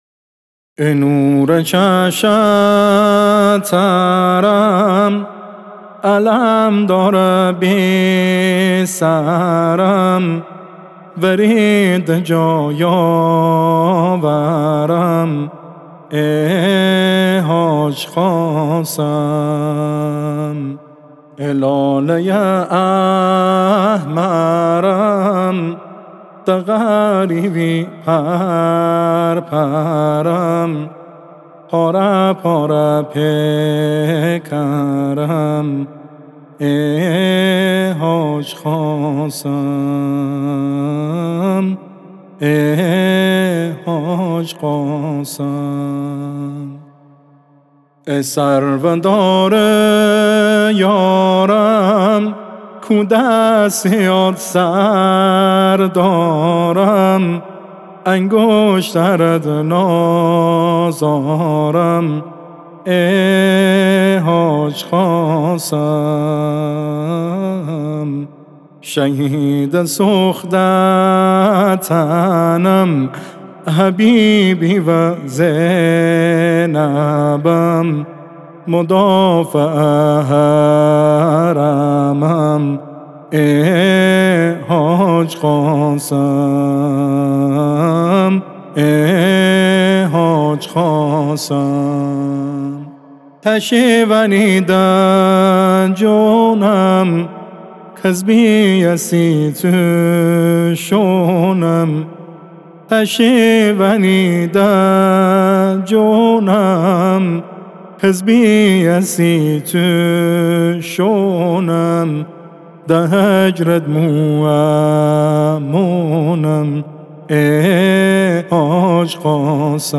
قطعه لری